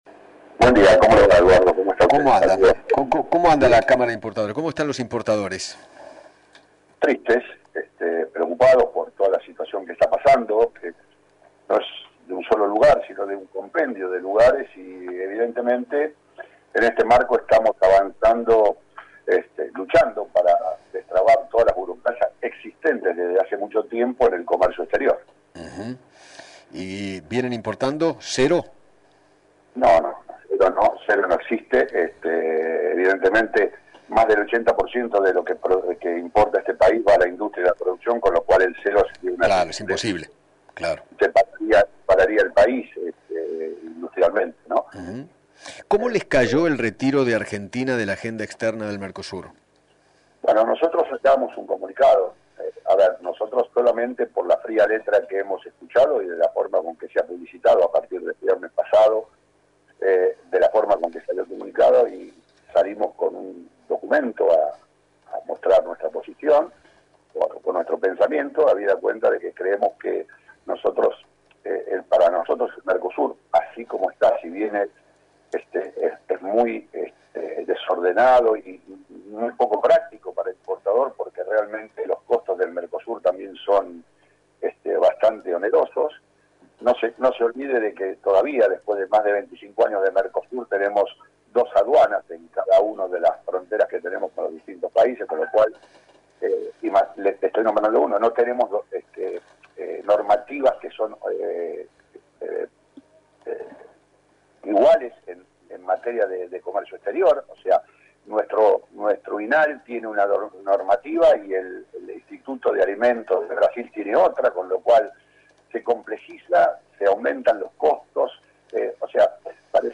dialogó con Eduardo Feinmann sobre el impacto de la Cuarentena en el sector y en la economía del país. Además, se refirió del retiro de Argentina de las negociaciones en la mesa del Mercosur.